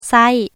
チャンパーサック [/sây/ ] (高いところから下がる声調)